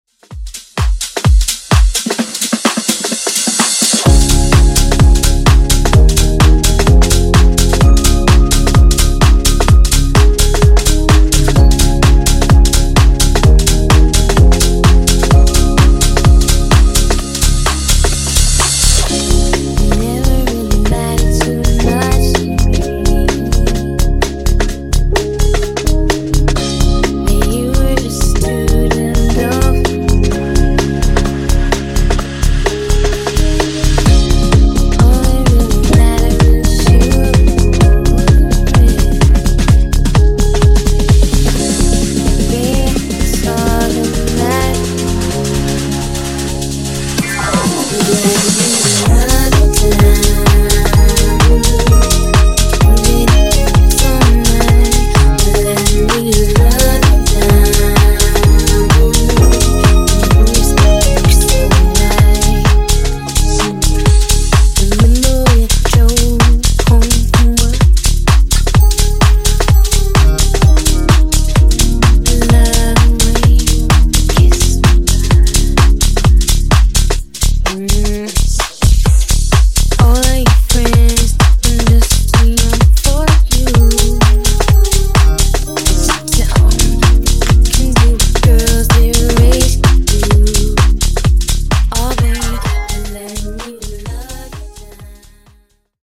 Genres: DANCE , RE-DRUM , TOP40
Clean BPM: 128 Time